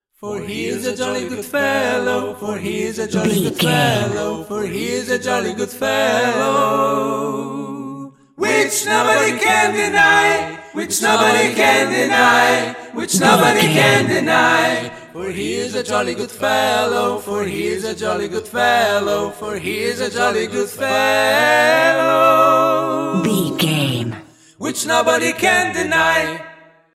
Aeolian/Minor
A♭
fun
groovy